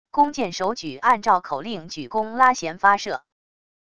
弓箭手举按照口令举弓拉弦发射wav音频